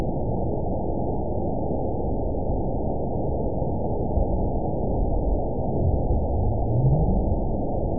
event 914280 date 05/02/22 time 23:07:43 GMT (3 years ago) score 9.37 location TSS-AB02 detected by nrw target species NRW annotations +NRW Spectrogram: Frequency (kHz) vs. Time (s) audio not available .wav